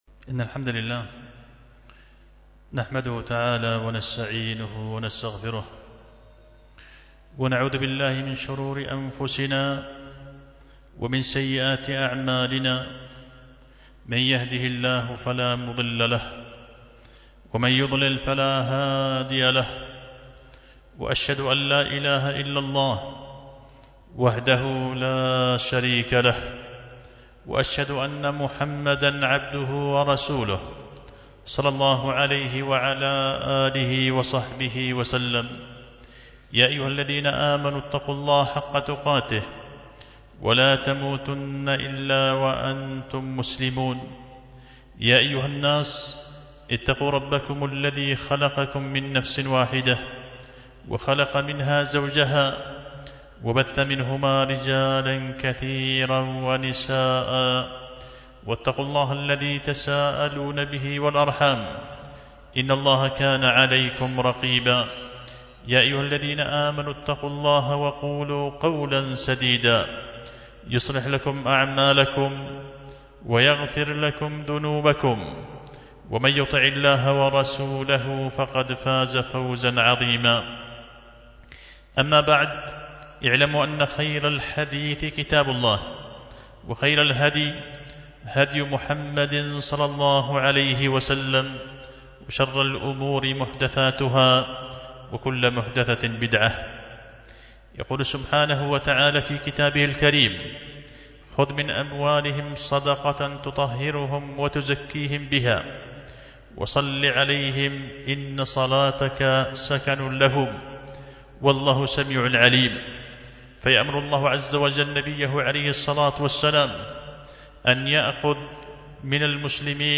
خطبة جمعة بعنوان التنبيهات المنتقاة من أخطاء الناس في الزكاة